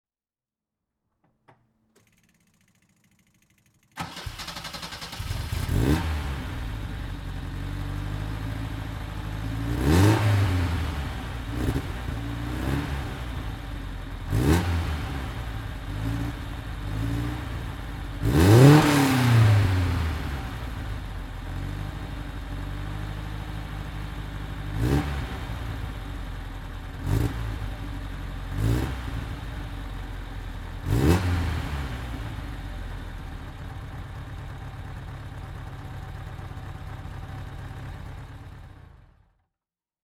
Alfa Romeo Giulia SS (1964) - Starten und Leerlauf
Alfa_Romeo_Giulia_SS_1964.mp3